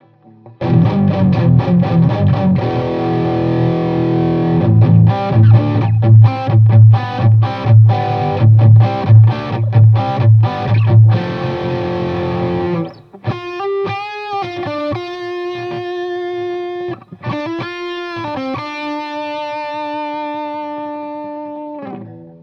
V BTE  Audio High Gain Amplifier  som stiahol Basy z 3/10 na 0,7/10 a vysky naplno, plus za konvoluciou jeden EQ s pridanymi vyskami len tak od oka.